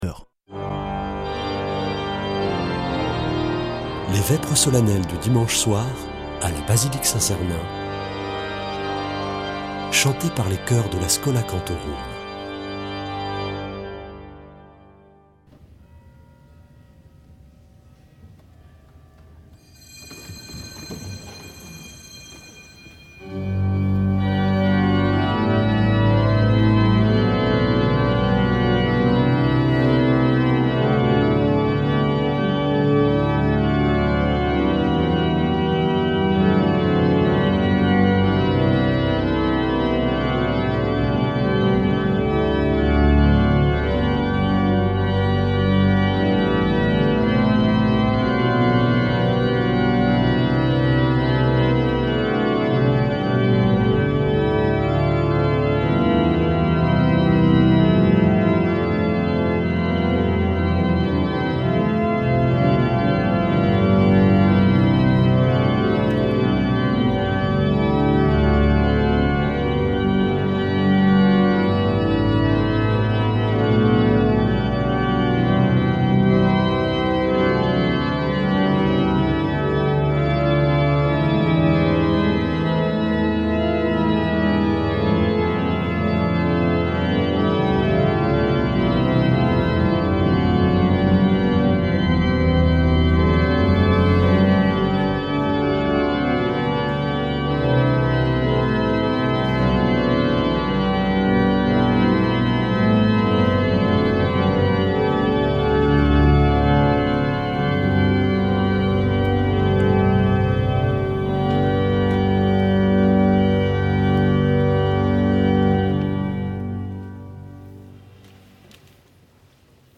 Vêpres de Saint Sernin du 14 janv.
Une émission présentée par Schola Saint Sernin Chanteurs